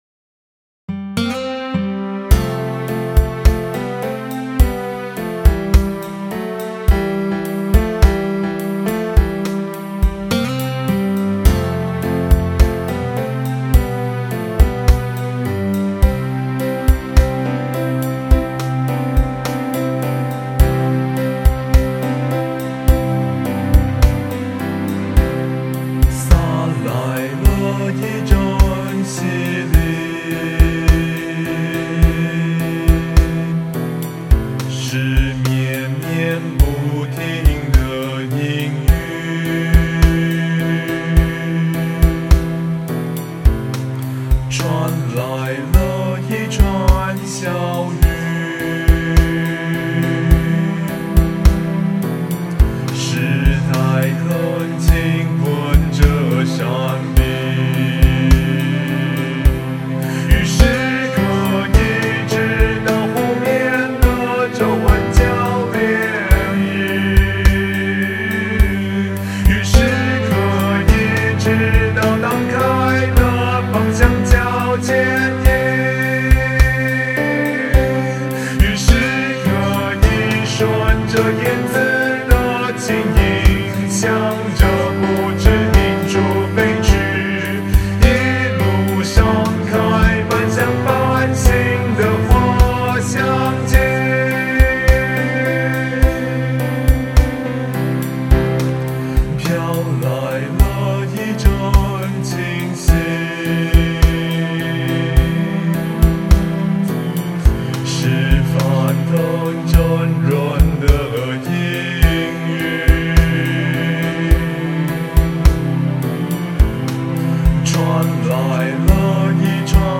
此曲清新、自然，但個人風格極強，非有靈心善感兼明世事者，無法發揮其中意境。